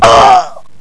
scream3.wav